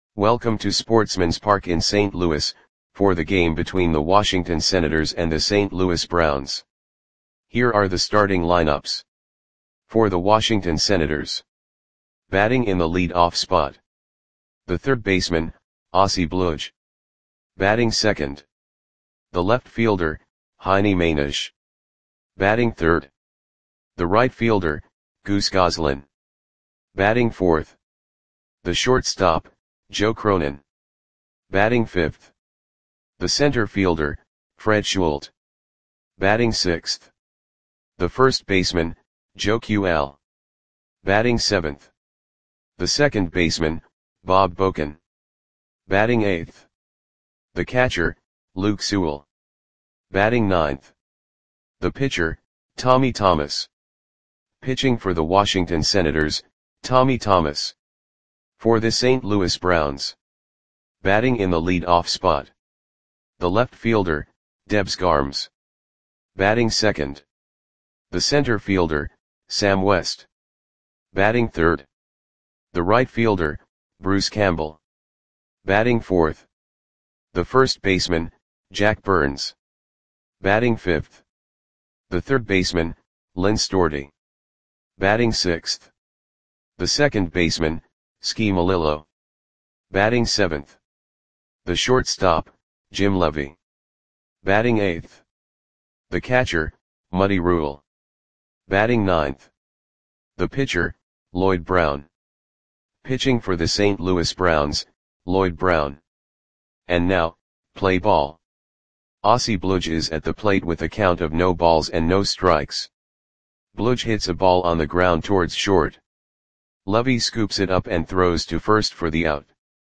Audio Play-by-Play for St. Louis Browns on May 8, 1933
Click the button below to listen to the audio play-by-play.